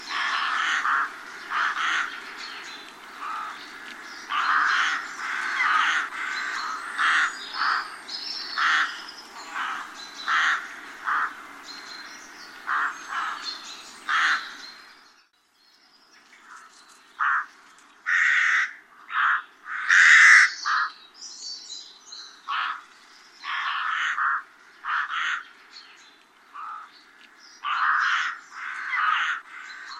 corbeau-freux.mp3